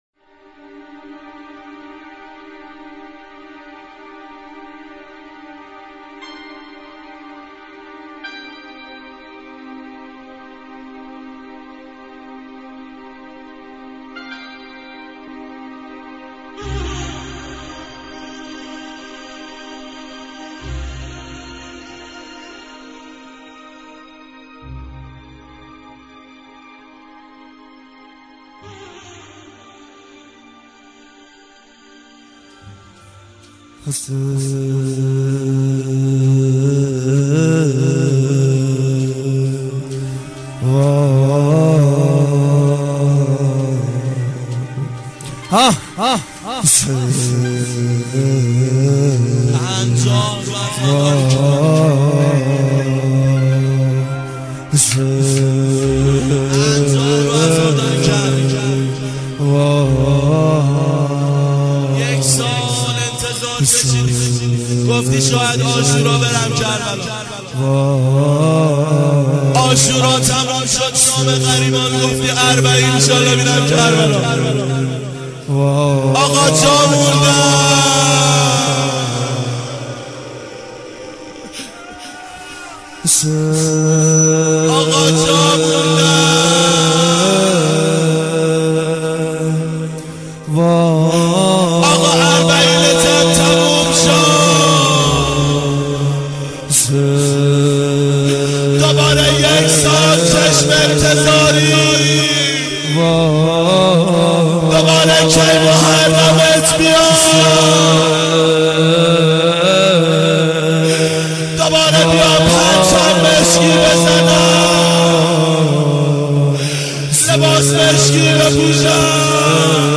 نواهنگ زیبا-درد دل و روضه
اربعین 1389 هیئت متوسلین به امیرالمومنین علی علیه السلام